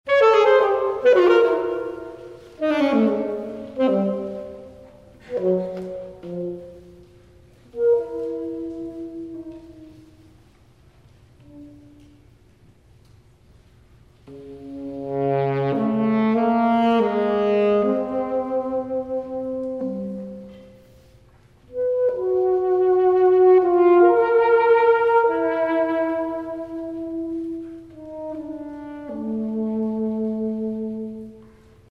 Saxophone solo